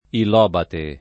[ il 0 bate ]